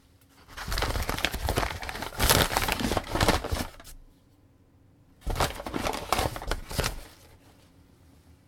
Sound：Paper
紙を握りつぶす